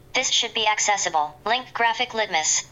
Screen reader: Accessible email header